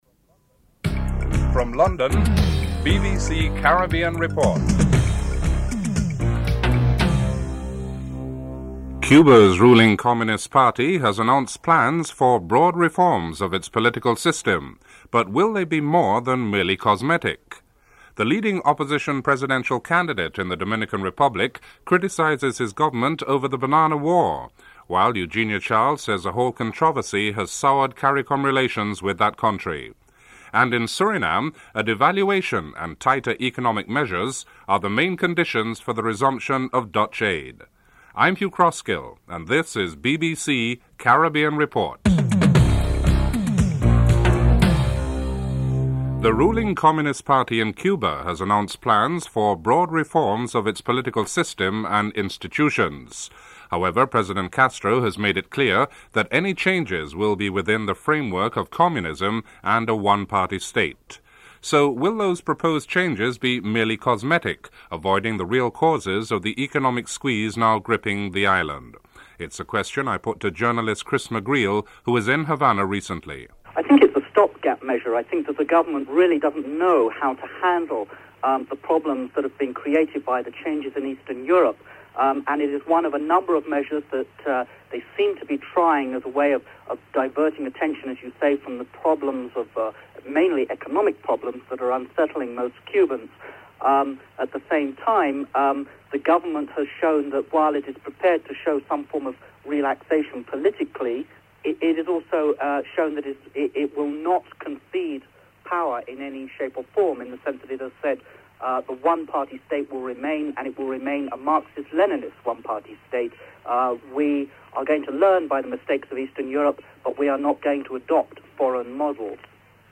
Includes a musical interlude at the beginning of the report.